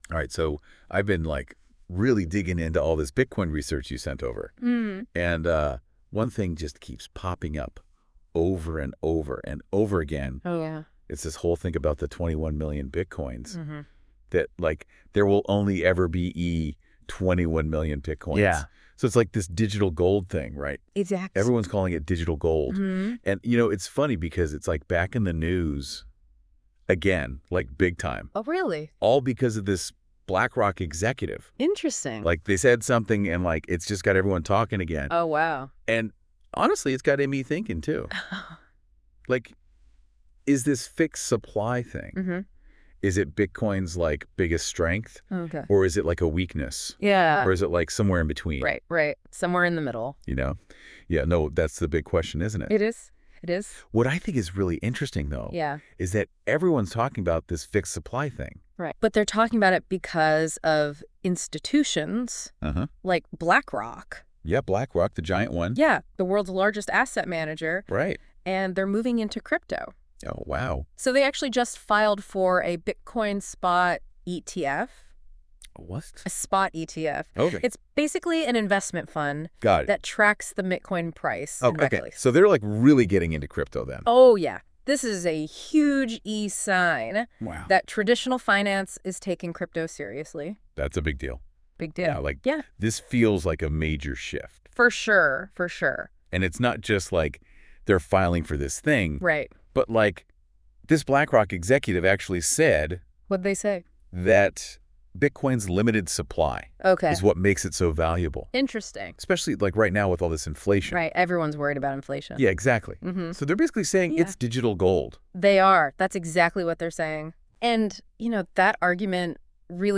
Podcast Discussion: Deep Dive Into This Article.